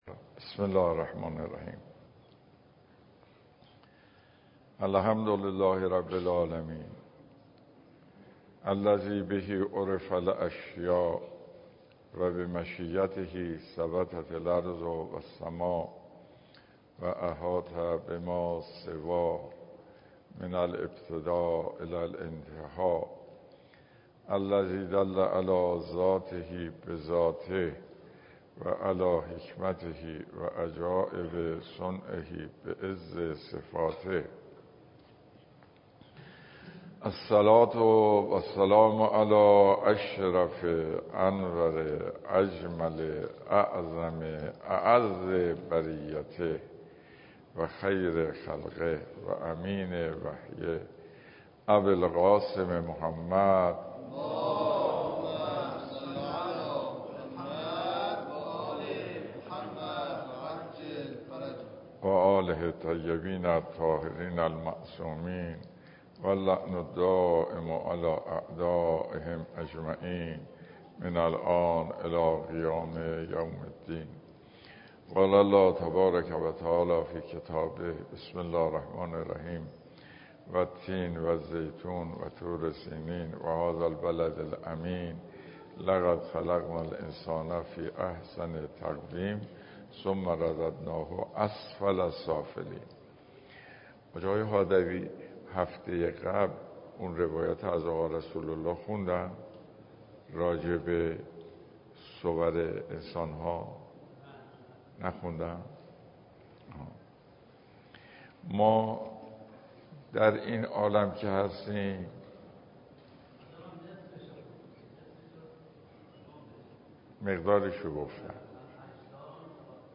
جلسات درس اخلاق